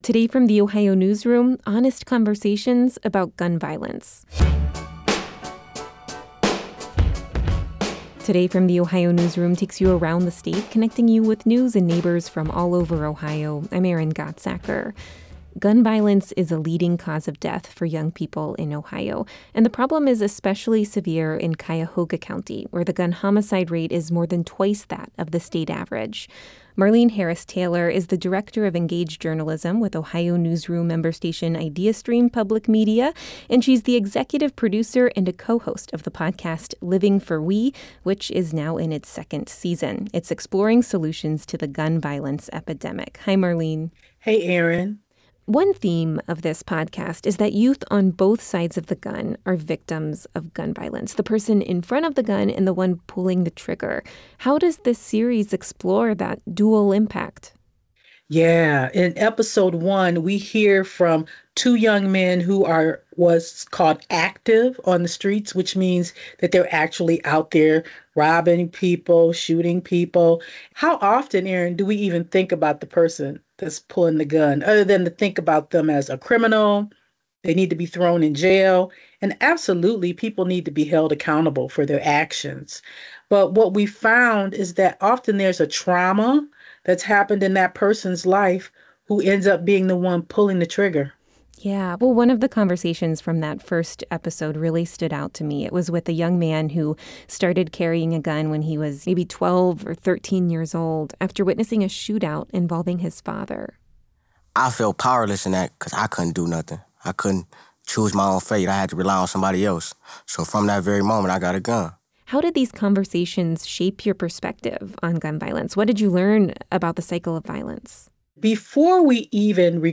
This conversation has been lightly edited for clarity and brevity.